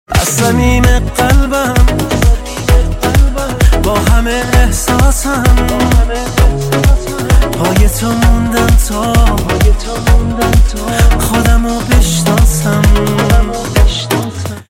رینگتون پرانرژی و باکلام